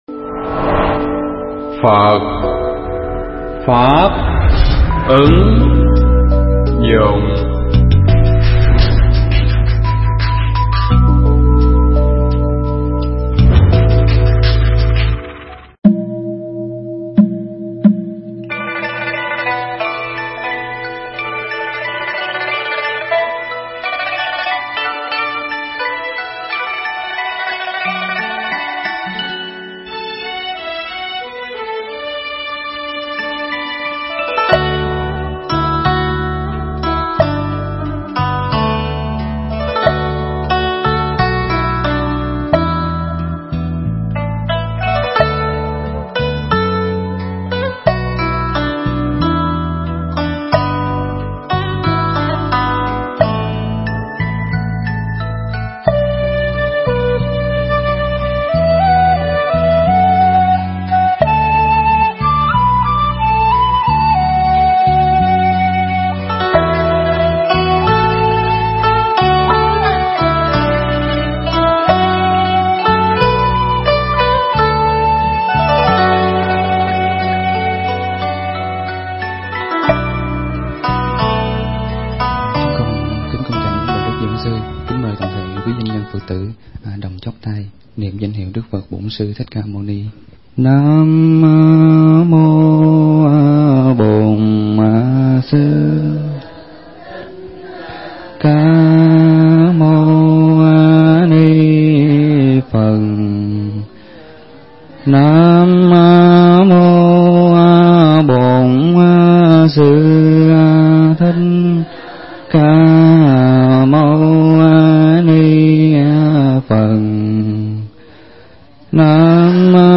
pháp thoại
tại khách sạn Sherwood Residence - quận 3, Tp.HCM